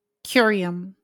Ääntäminen
IPA : /ˈkjʊəɹiəm/